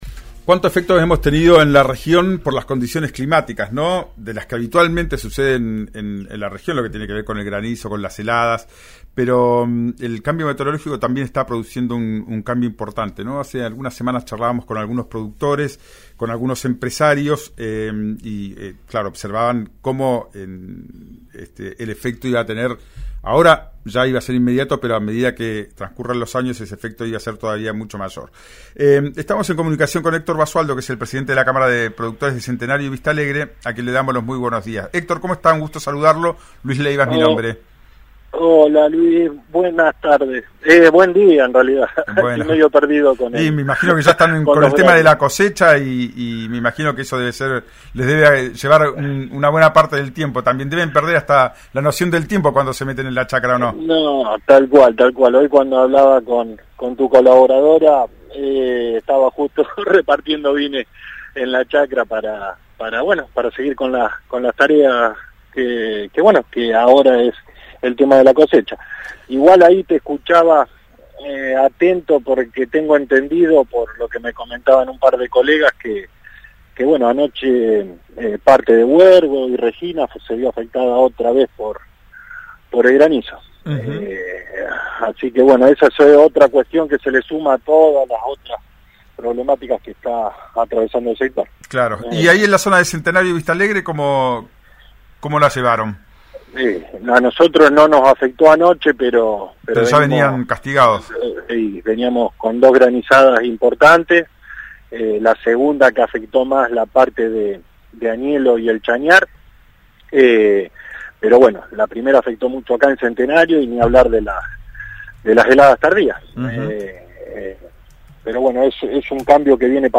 dialogó en radio Cumbre